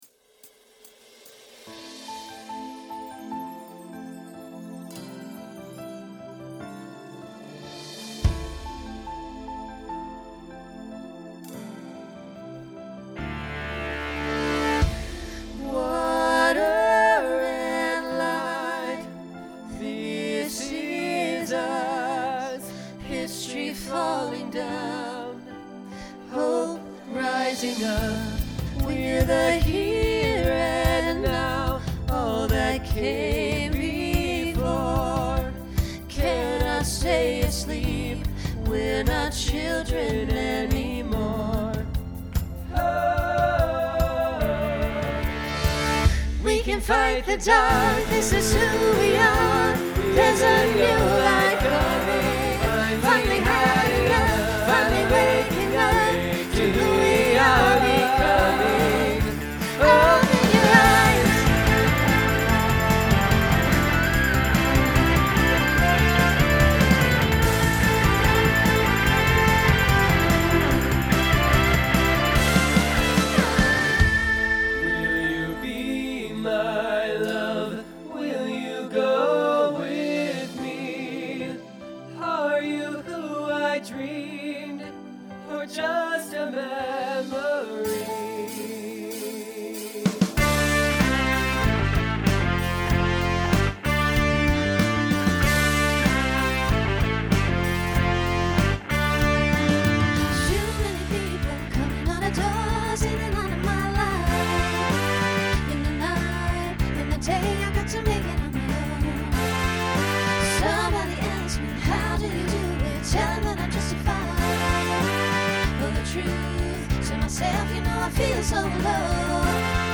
Genre Pop/Dance , Rock Instrumental combo
Transition Voicing Mixed